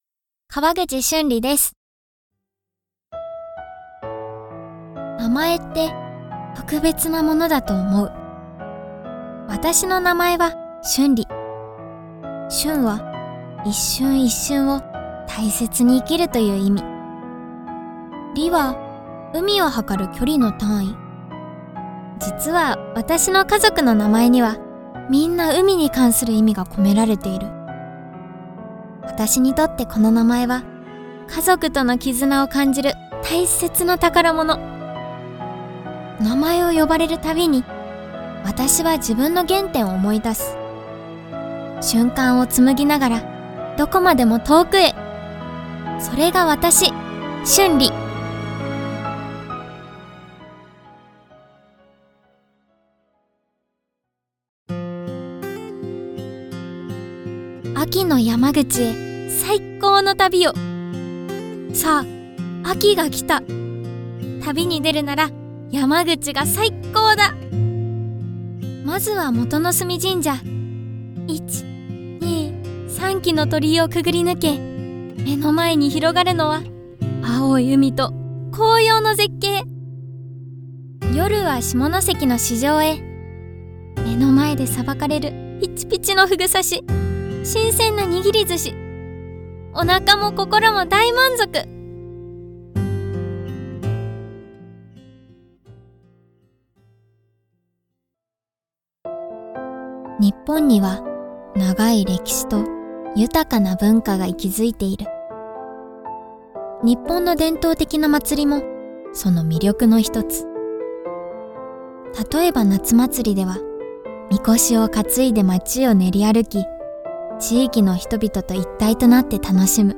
幼い甘く可愛い声